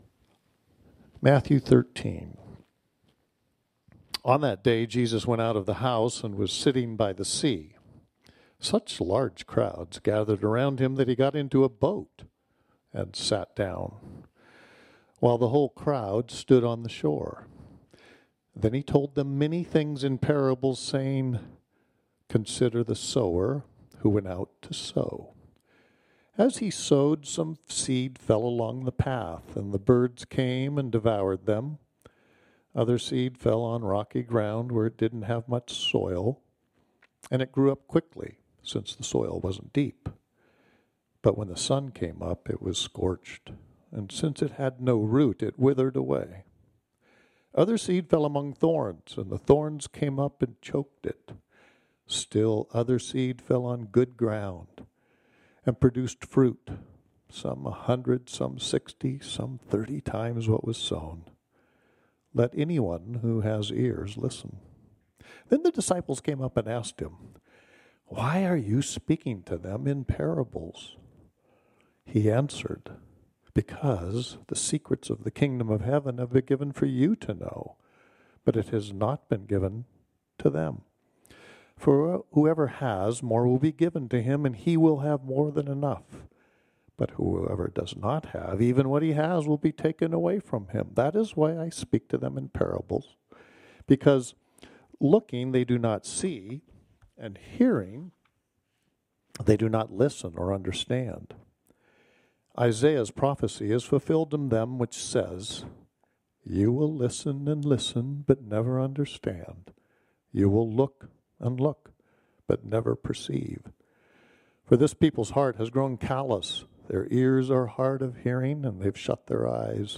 This sermon was originally preached on Sunday, June 9, 2024.